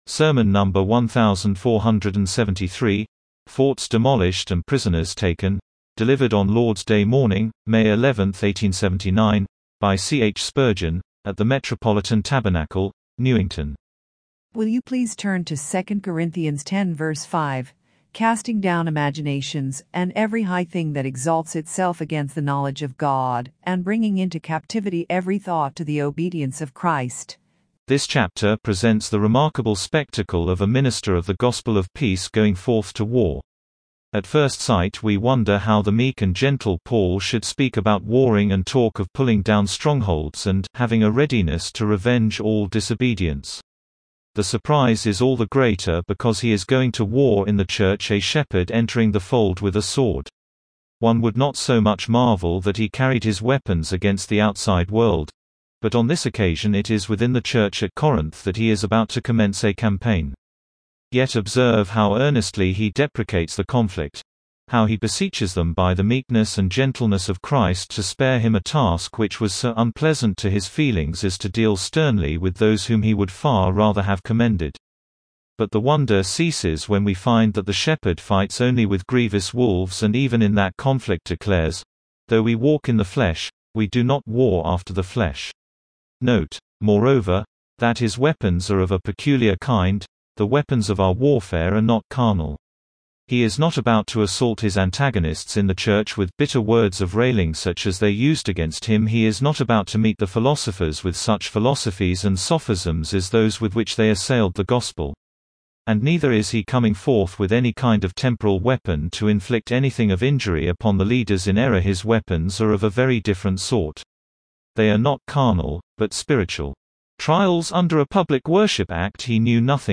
Sermon number 1,473, FORTS DEMOLISHED AND PRISONERS TAKEN